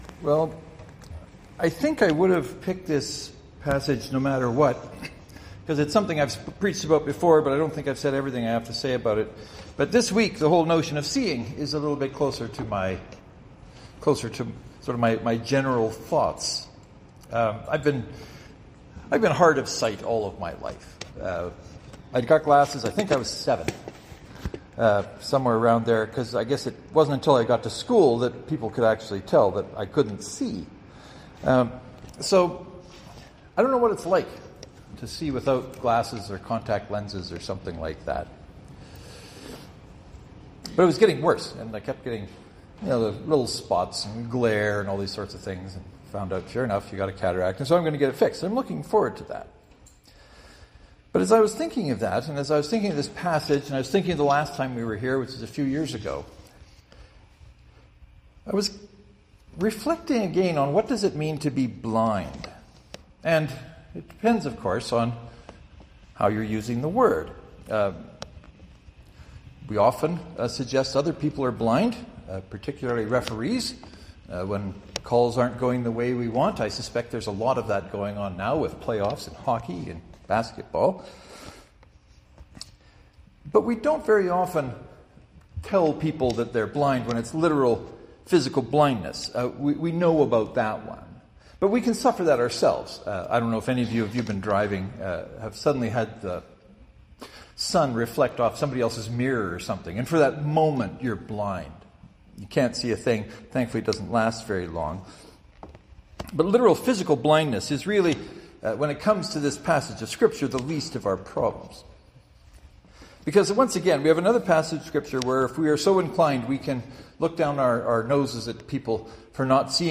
There is only one sermon this week as the recent weather has made some of the non-primary roads quite unpleasant.
St. Mark’s Presbyterian (to download, right-click and select “Save Link As .